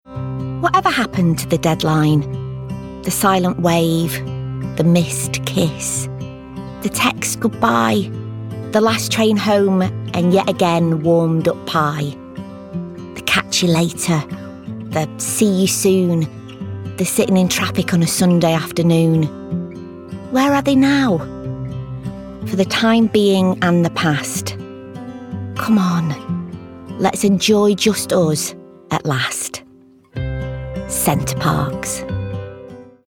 ***NEW ARTIST*** | 50s | Warm, Quirky & Natural
Voice reel